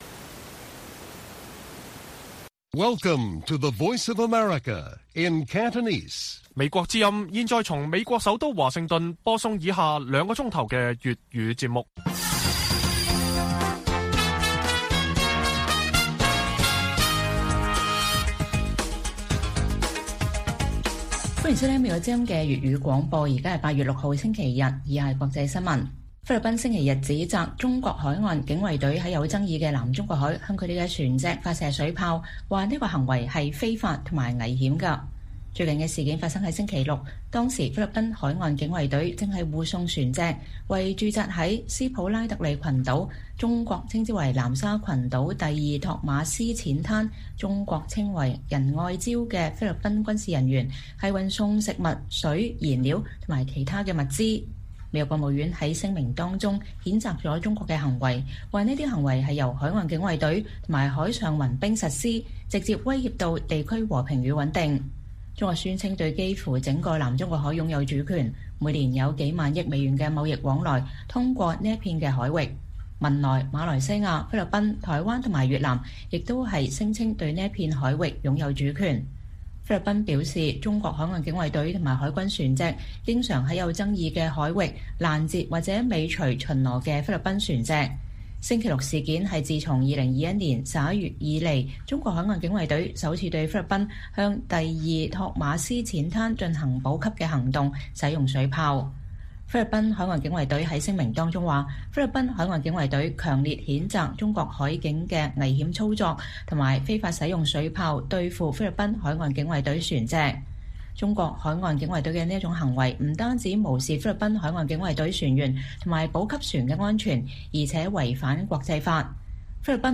粵語新聞 晚上9-10點: 菲律賓指責中國海岸警衛隊向其船隻發射水砲